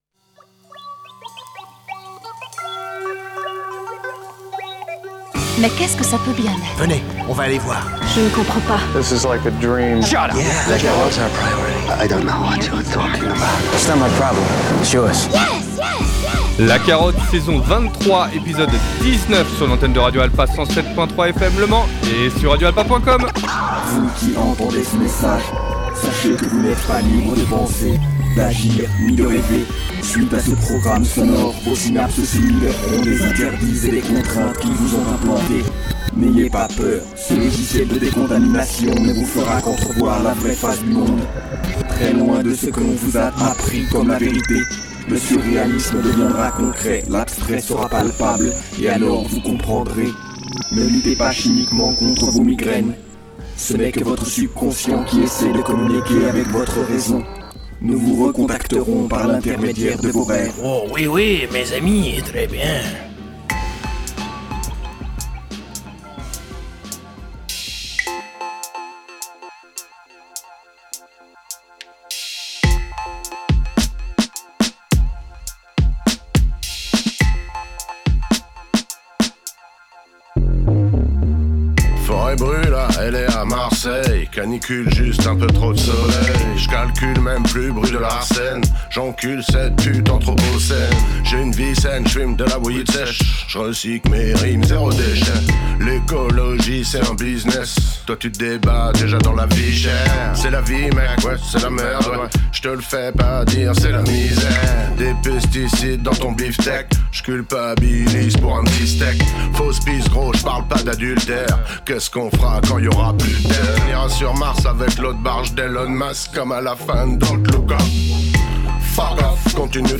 Un épisode résolument Boom Bap pour le meilleur et le meilleur bien évidemment. Une émission qui mélange de glorieux anciens avec des sophomores qui méritent de l’attention auditive.